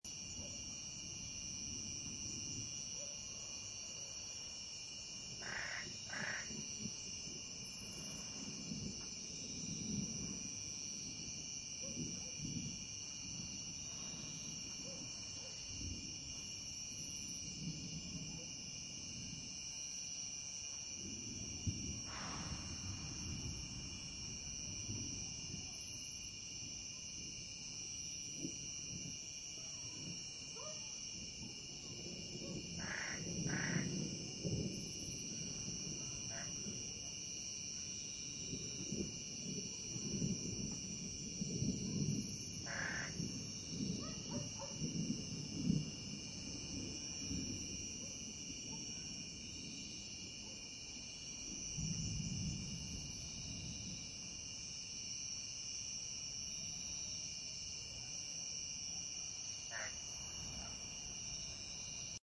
Cicada Far Thunder Soundboard: Play Instant Sound Effect Button